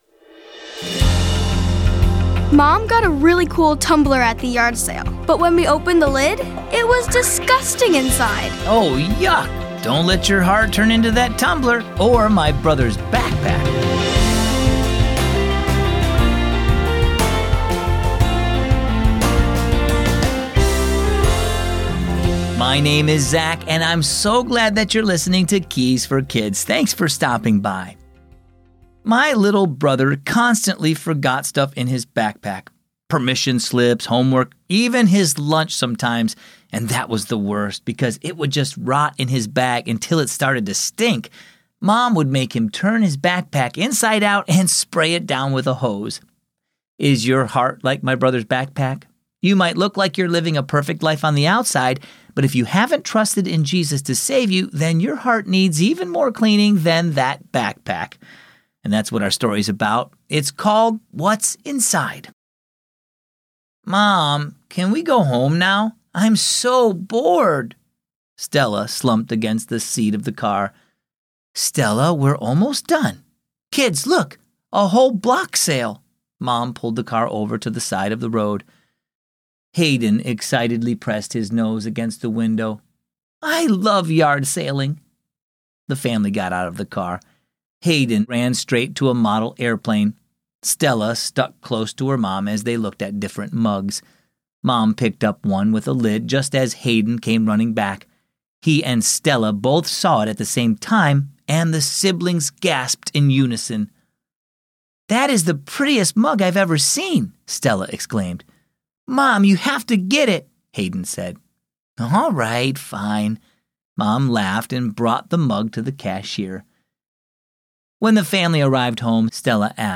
Bedtime Stories Religion Christianity Keys For Kids Ministries Bible Reading Society Philosophy
Keys for Kids is a daily storytelling show based on the Keys for Kids children's devotional.